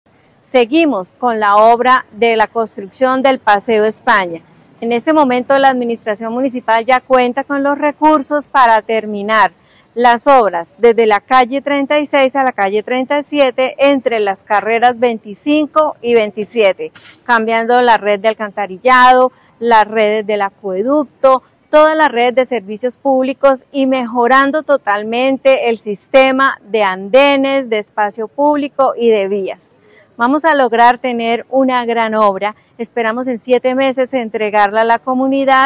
María del Rosario Torres, Secretaria de Infraestructura de Bucaramanga